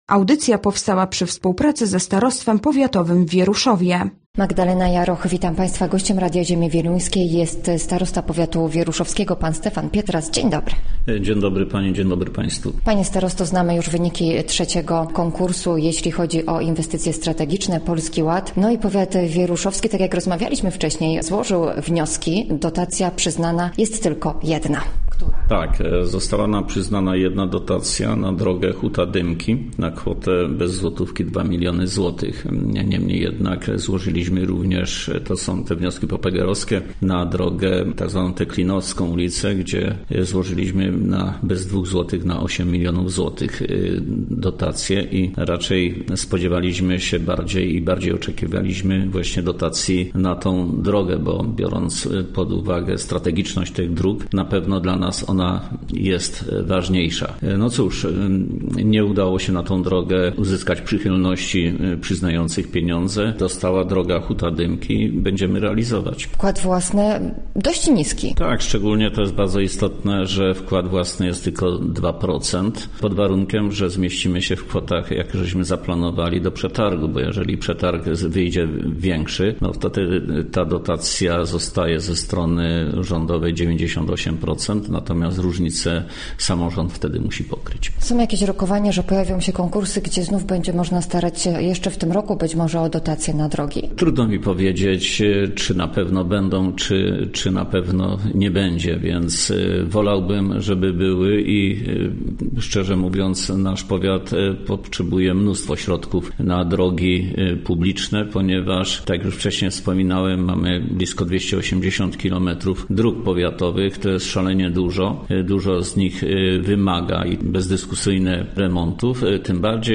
Gościem Radia ZW był Stefan Pietras, starosta powiatu wieruszowskiego